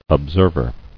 [ob·serv·er]